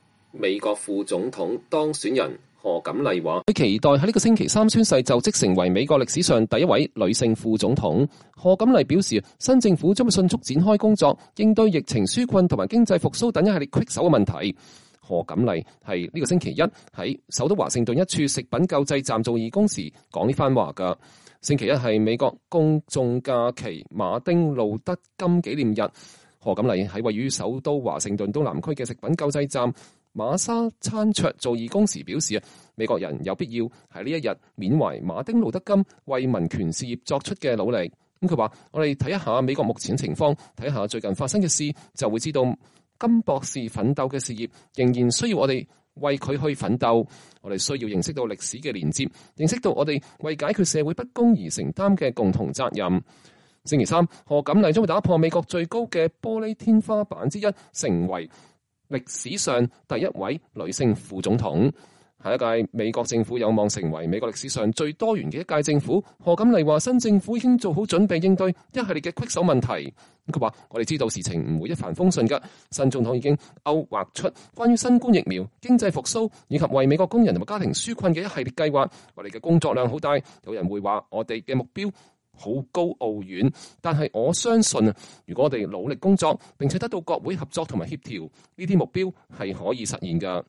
賀錦麗是本週一在首都華盛頓一處食品救濟站做義工的時候說這番話的。